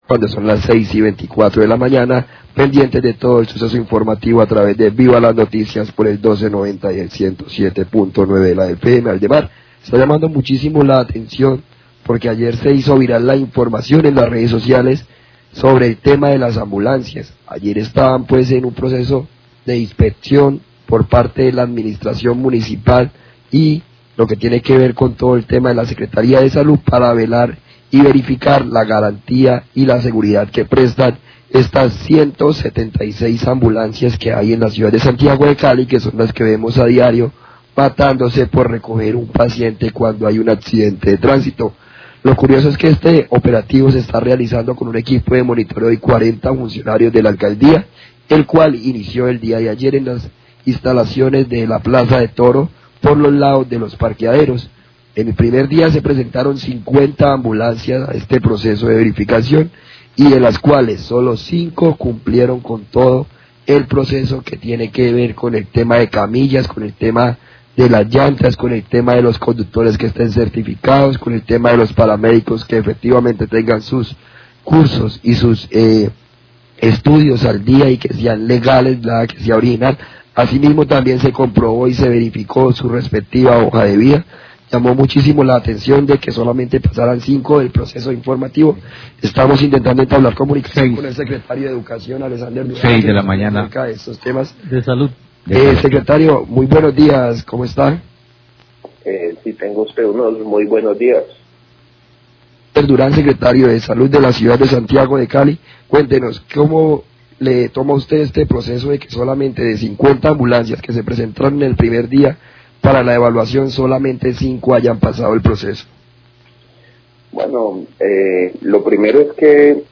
Radio
El Secretario de Salud, Alexander Durán, habló sobre los hallazgos en la primera jornada de revisión de las ambulancias donde 5 de ellas, no habrían pasado la revisión por algunos inconvenientes técnicos.